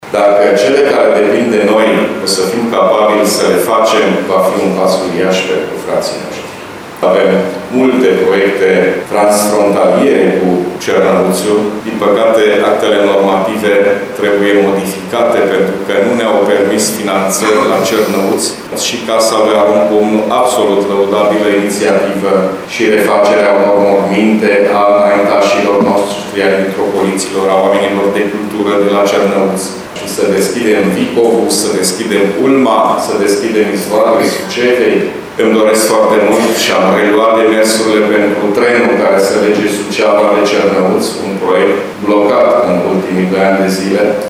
Ședință solemnă la Palatul Administrativ Suceava, de Ziua Bucovinei
Președintele Consiliului Județean GHEORGHE FLUTUR  a făcut apel la unitate, spunând că trebuie întărite relațiile cu românii din Bucovina de Nord.